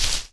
default_dig_crumbly.2.ogg